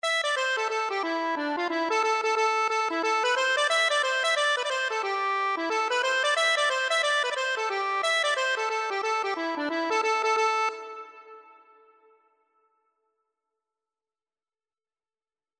Free Sheet music for Accordion
2/4 (View more 2/4 Music)
A minor (Sounding Pitch) (View more A minor Music for Accordion )
Traditional (View more Traditional Accordion Music)